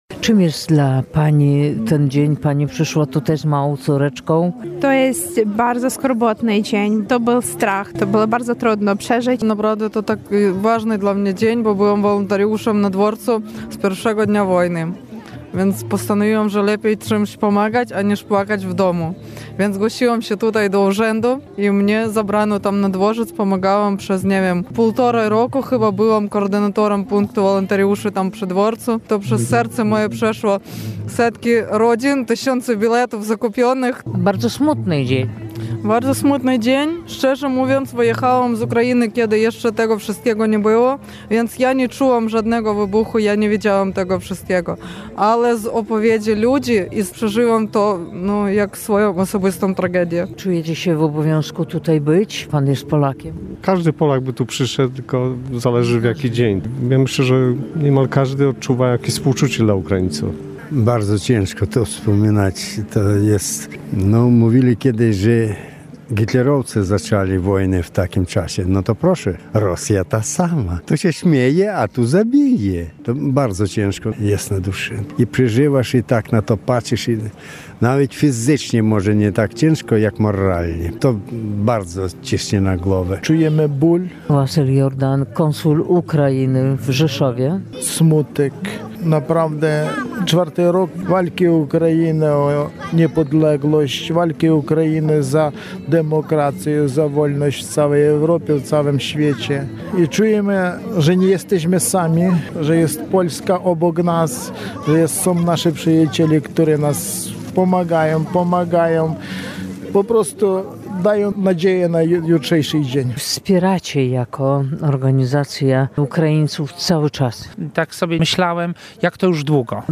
Relacje reporterskie • W czwartą rocznicę pełnoskalowej inwazji Rosji na Ukrainę mieszkańcy Rzeszowa i żyjący w mieście obywatele Ukrainy spotkali się na Rynku.
Spotkanie solidarnościowe na Rynku w Rzeszowie
Ukraińcy mówili, że to dla nich bardzo smutny dzień.